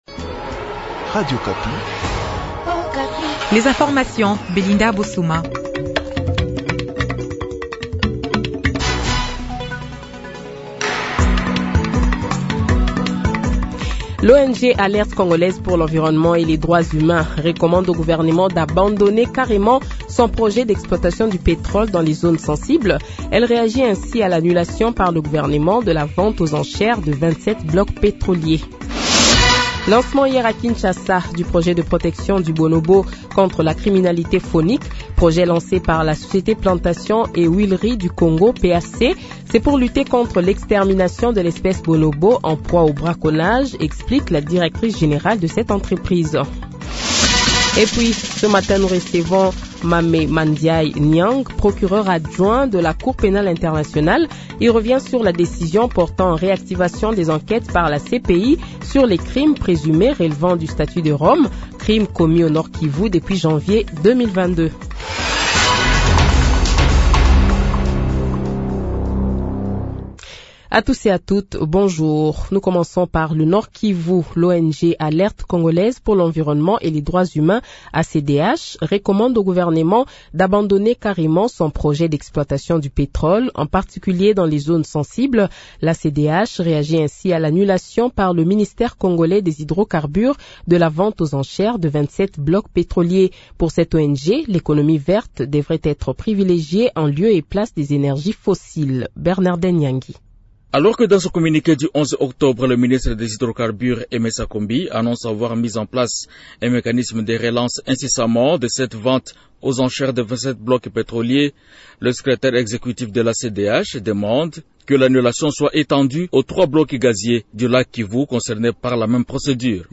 Le Journal de 8h, 17 Octobre 2024 :